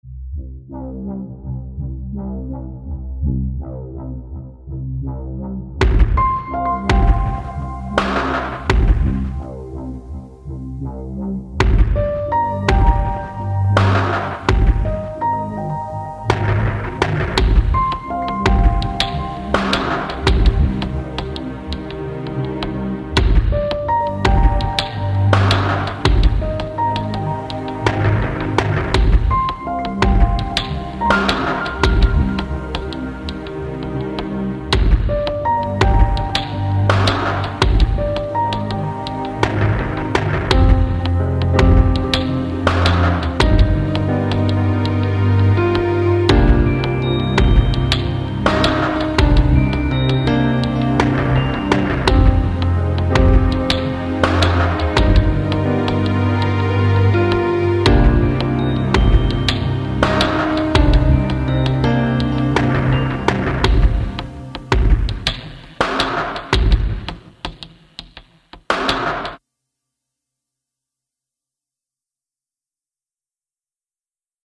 Dramatic soundtrack I envisioned for a tension-filled visual-novel scene :P anime POV: You cut ties with your childhood best friend and swear to become stronger than them after they betray you. However, you are filled with pain and loneliness more than anger......